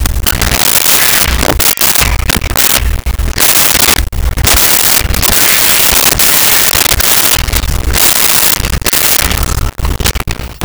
Monkey Screeches
Monkey Screeches.wav